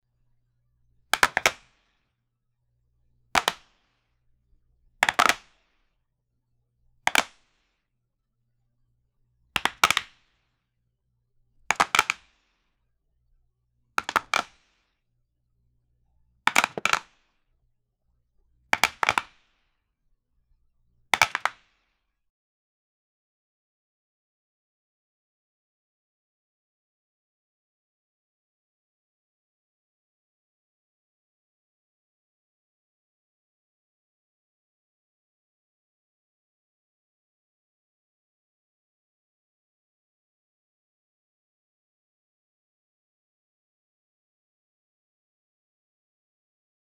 Casinos Sound Effects - Free AI Generator & Downloads
poker-winning-sound-3rvuf6gk.wav